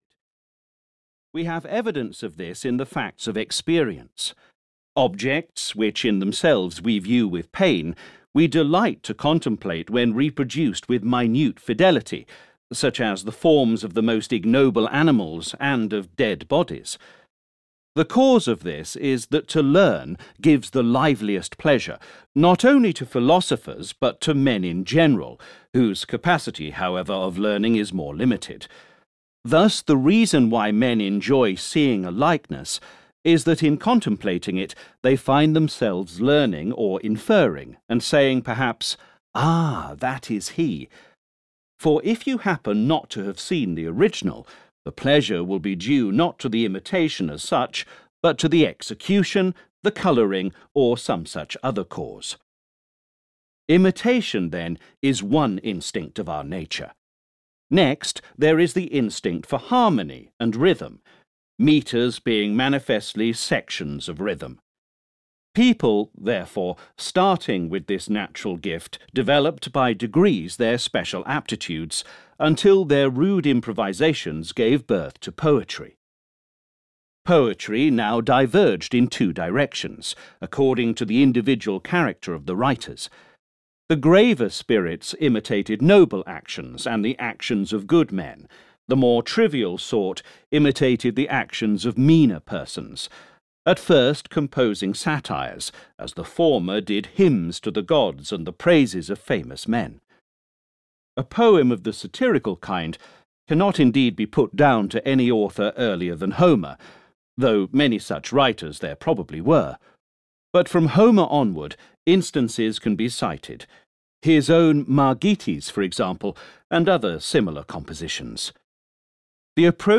Audio knihaPoetics/Rhetoric (EN)
Ukázka z knihy